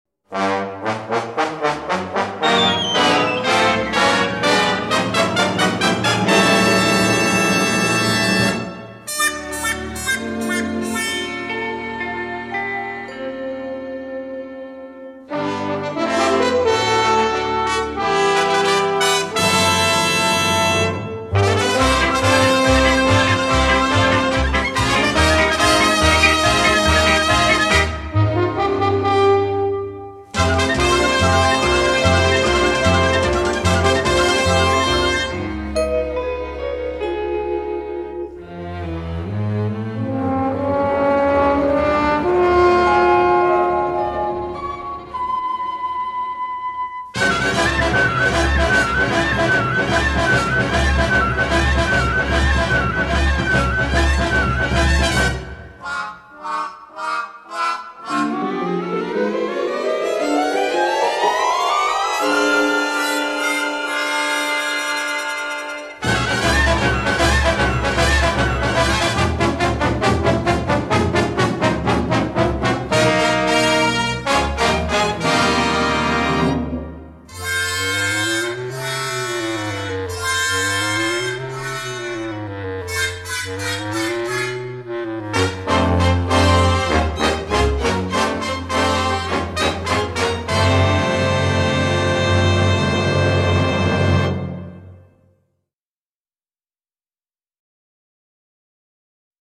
avec de nombreuses ambiances exotiques.